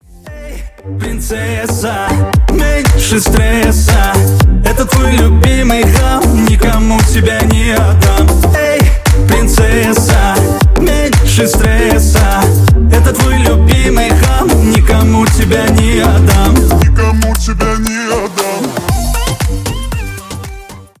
поп
позитивные
зажигательные
веселые